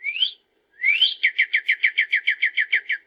Add bird sounds (CC0)
sounds_bird_02.ogg